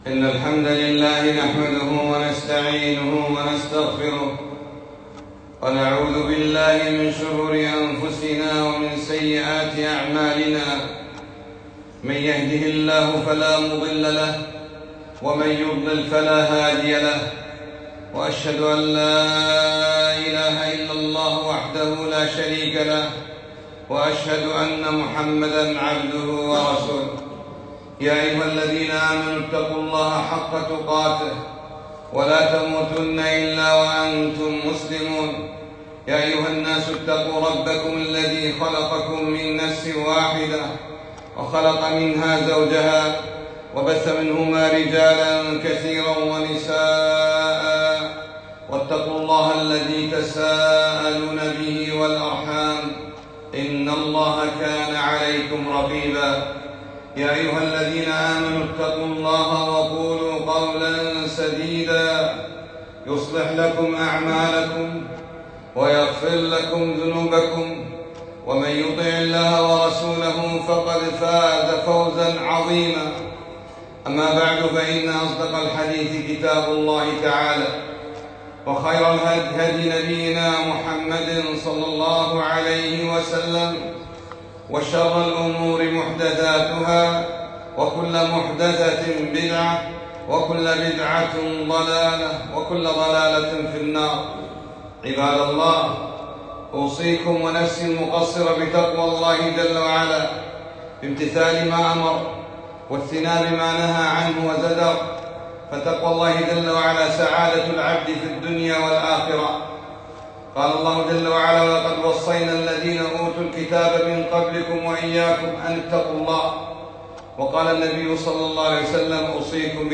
خطبة - شهد الله أنه لا إله إلا هو